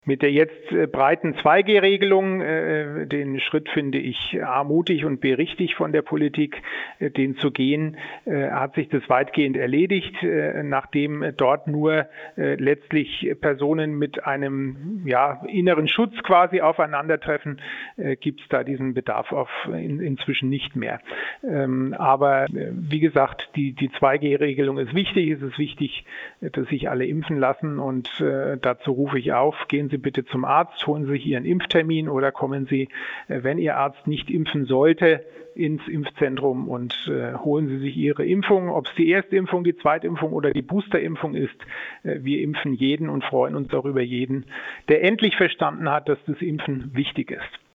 Impfkapazität, Boostern und 3G-Kontrollen: Schweinfurts Ordnungsreferent Jan von Lackum im Interview - PRIMATON